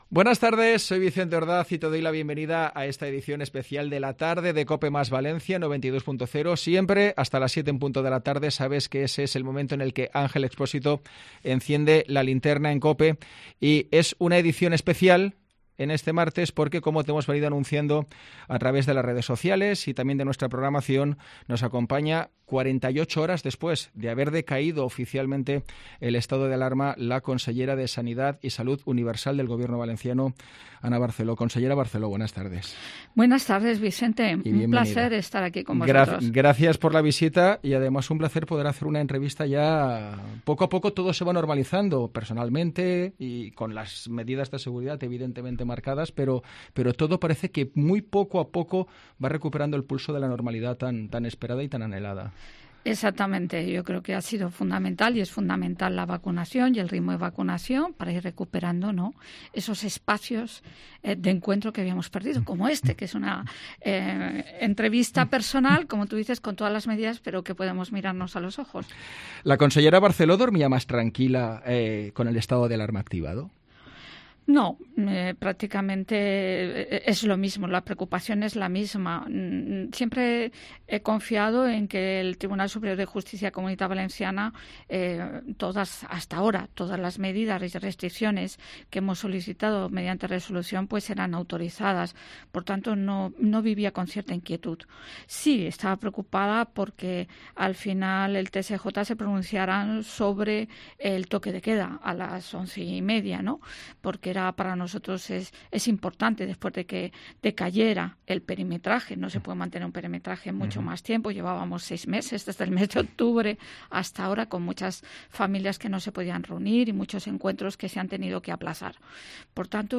La consellera de Sanidad Ana Barceló en los estudios de COPE Valencia